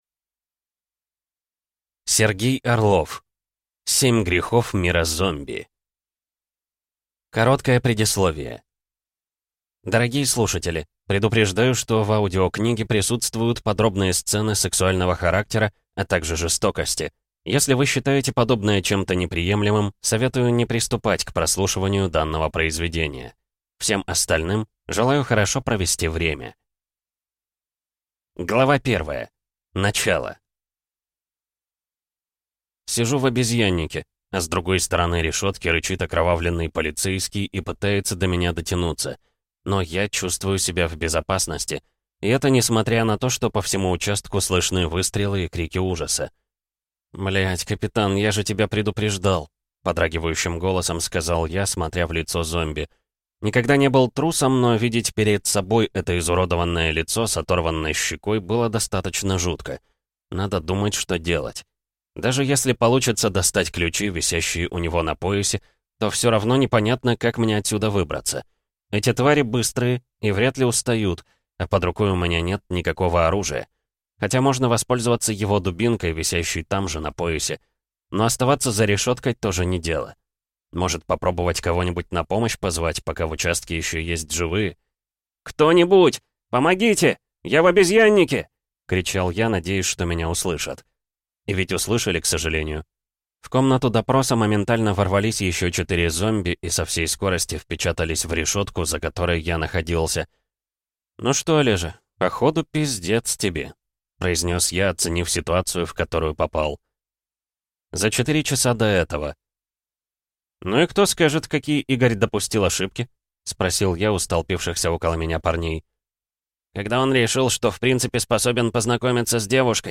Аудиокнига Семь грехов мира ЗОМБИ | Библиотека аудиокниг